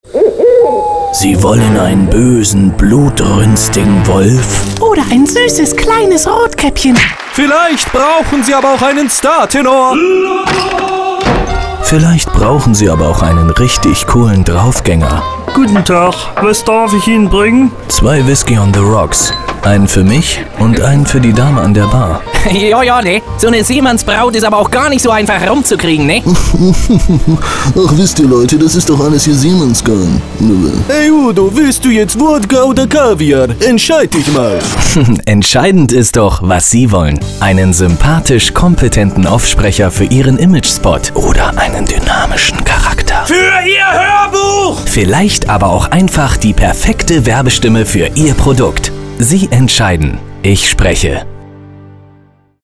professionell, vielseitig und musikalisch
Kein Dialekt
Sprechprobe: eLearning (Muttersprache):